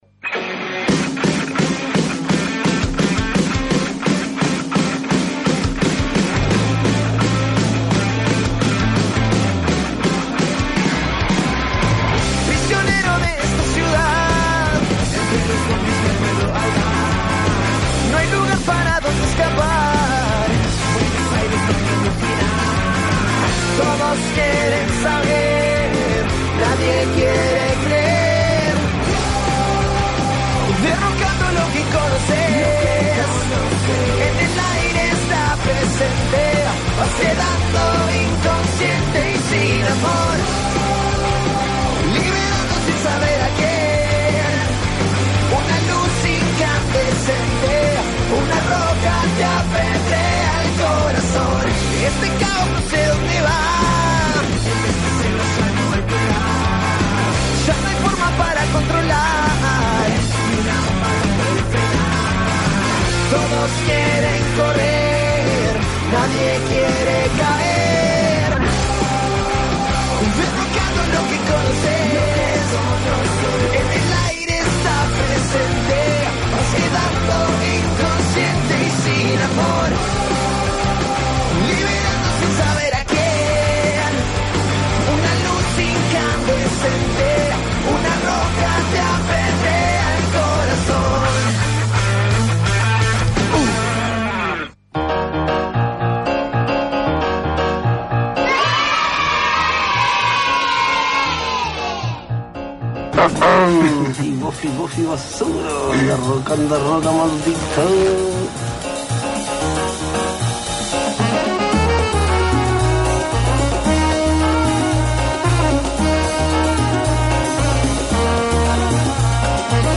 (Desde el minuto 13:14 hasta el minuto 26:25) _El sábado la mesa se dio un gusto, entrevistamos a Angel Cappa.